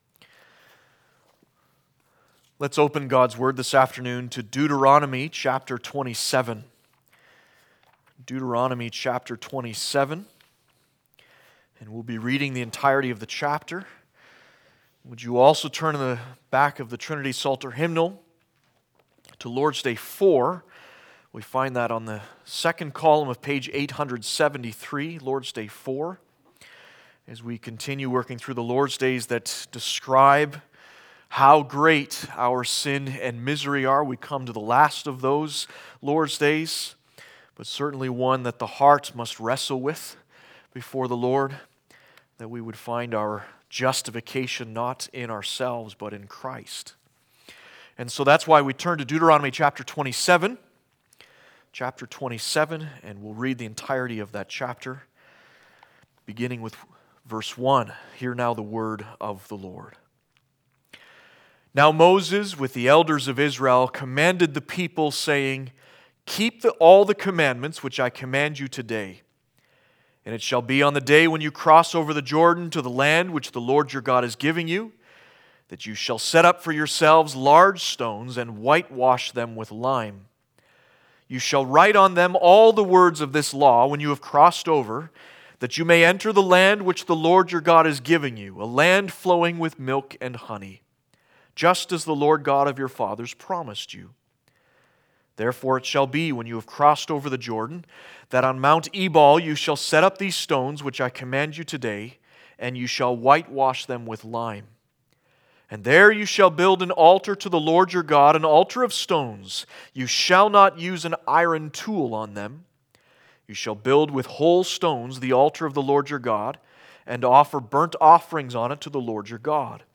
Passage: Deuteronomy 27 Service Type: Sunday Afternoon